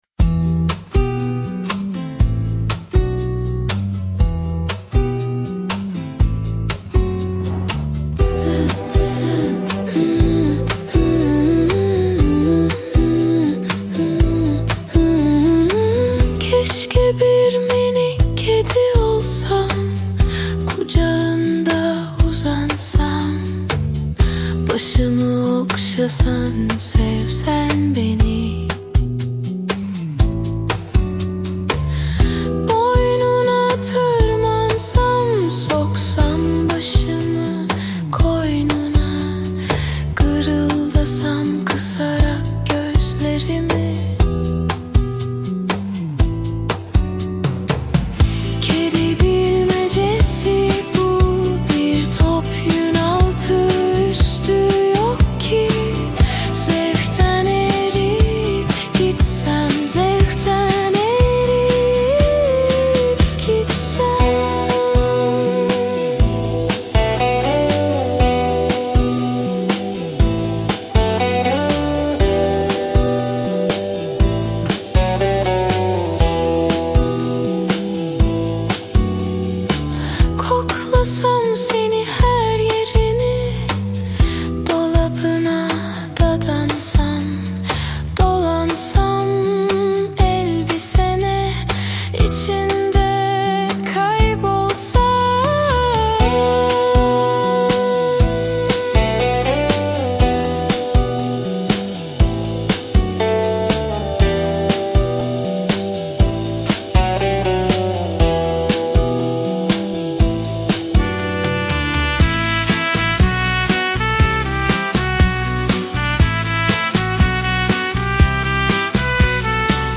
Tür : Pop